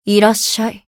灵魂潮汐-南宫凛-问候-不开心.ogg